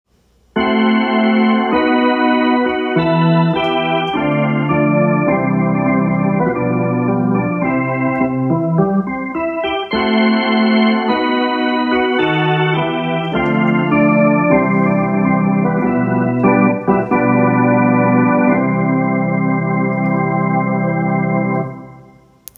RECESSIONAL MARCH